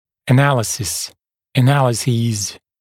[ə’næləsɪs] [ə’nælɪsiːz][э’нэлэсис] [э’нэлиси:з]анализ, анализы